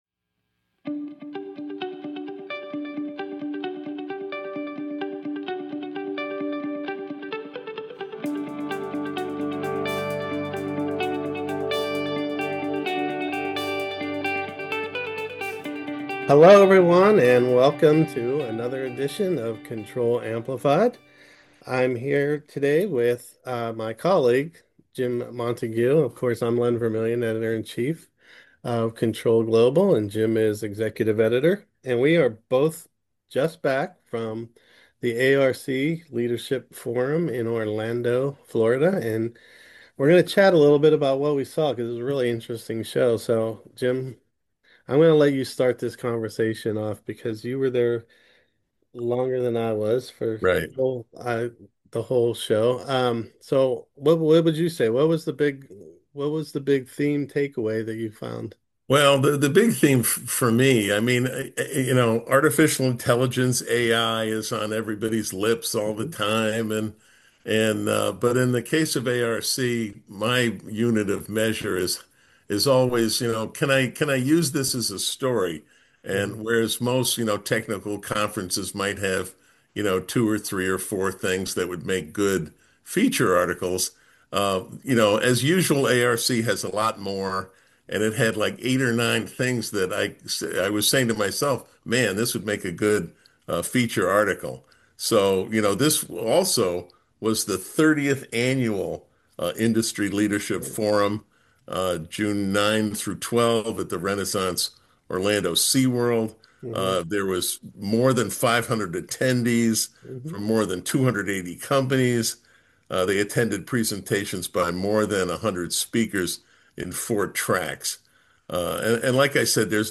A conversation about ARC Industry Leadership Forum with Control's editors